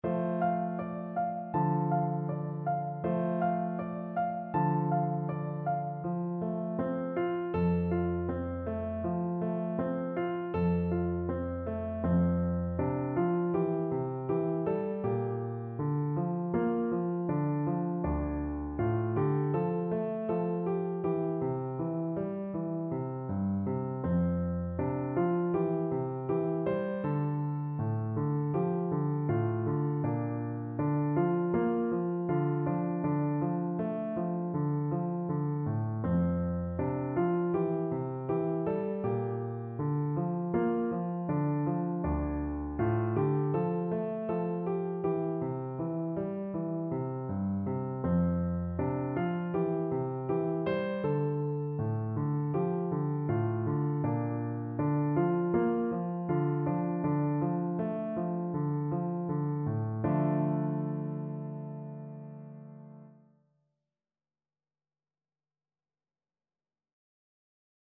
Piano version
No parts available for this pieces as it is for solo piano.
Andante
4/4 (View more 4/4 Music)
Traditional (View more Traditional Piano Music)
world (View more world Piano Music)